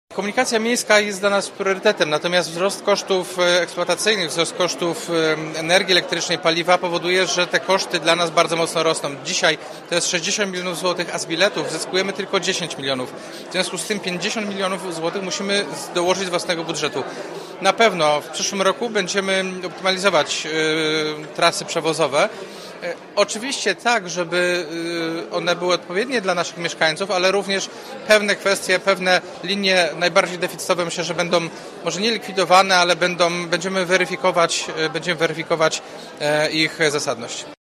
Dopiero po grudniowej sesji , prezydent Wójcicki przyznał, że choć komunikacja publiczna jest dla miasta priorytetem to zmiany będą: